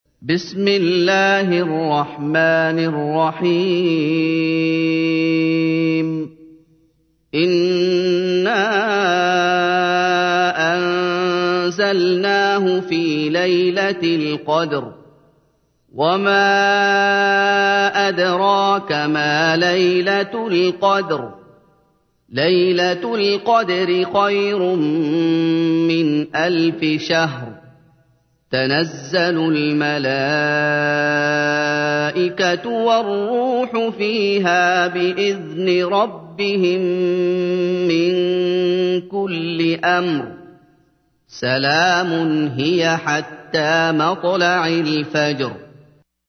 تحميل : 97. سورة القدر / القارئ محمد أيوب / القرآن الكريم / موقع يا حسين